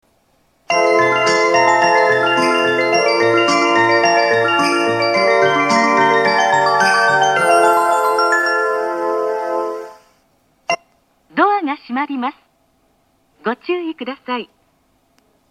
４番線発車メロディー 曲は「すすきの高原」です。